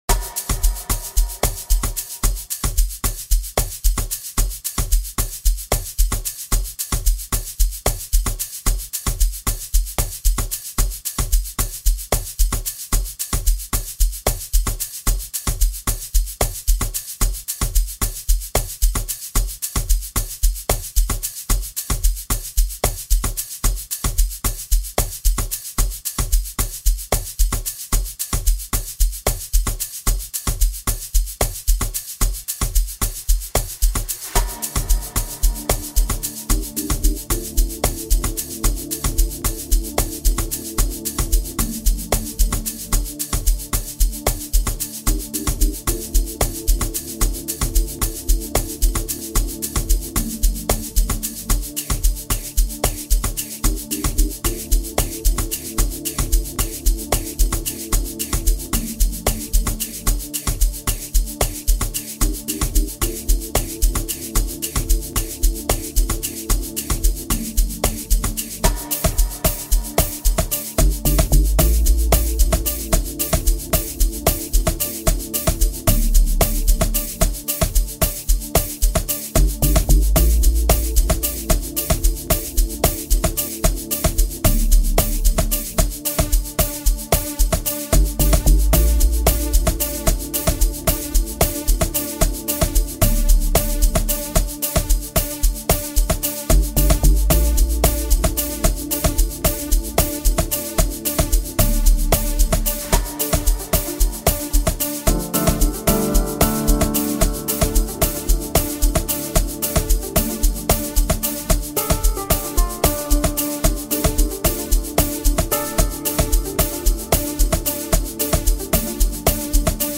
Private School Piano rendition